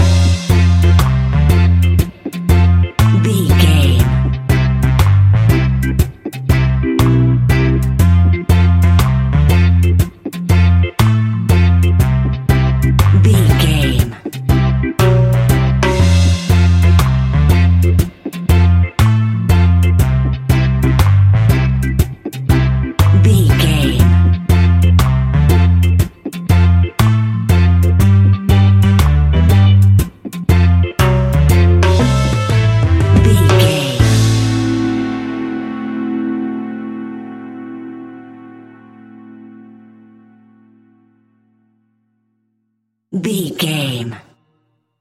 Classic reggae music with that skank bounce reggae feeling.
Aeolian/Minor
laid back
off beat
drums
skank guitar
hammond organ
horns